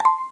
add touch event sounds using libcanberra
ding.ogg